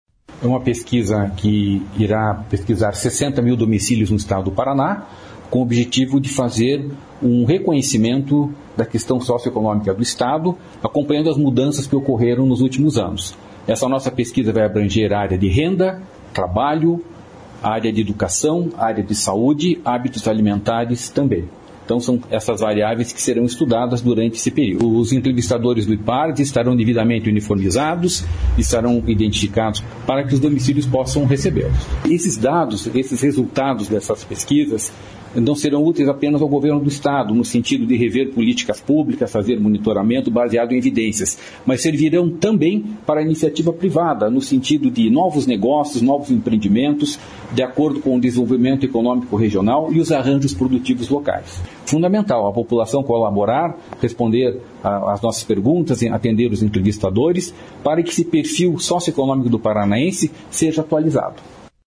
O principal objetivo da PAD-PR será reunir informações das famílias paranaenses para caracterizar a infraestrutura dos domicílios e identificar o perfil demográfico e socioeconômico dos moradores nas áreas urbanas e rurais do Paraná. O presidente do Ipardes, explica sobre a pesquisa.